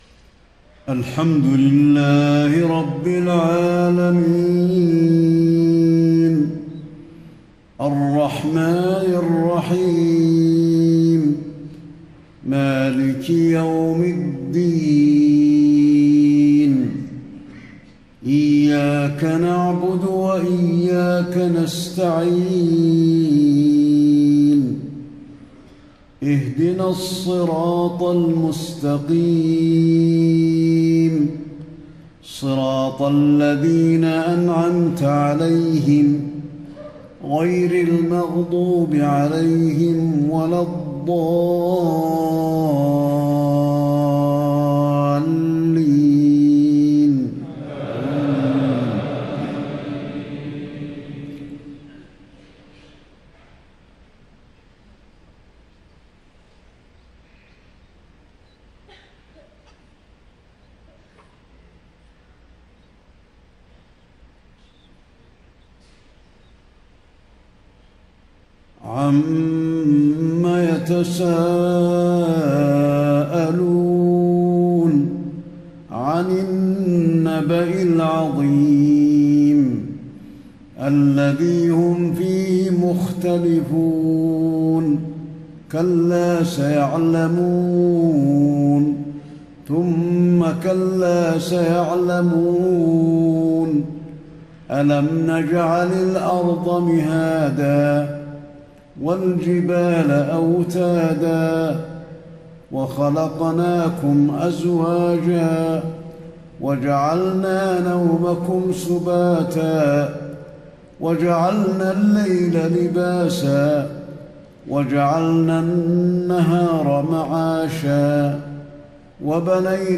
صلاة الفجر 28 رمضان 1435هـ سورة النبأ كاملة > 1435 🕌 > الفروض - تلاوات الحرمين